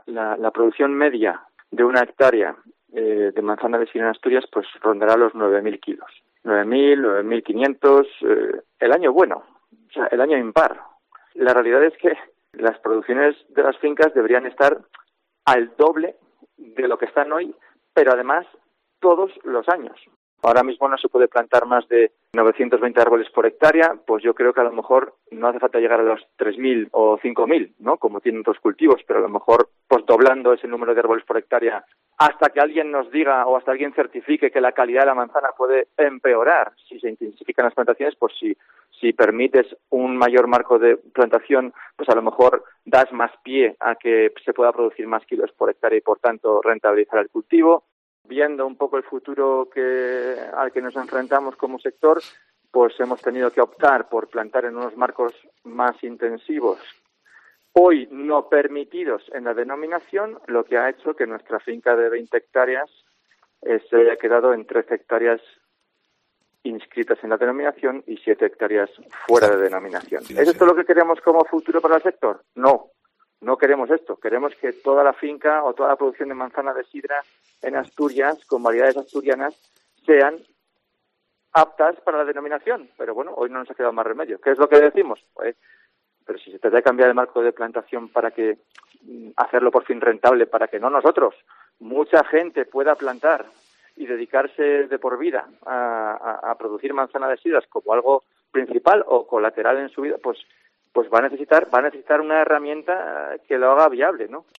productor de manzana de sidra, habla en COPE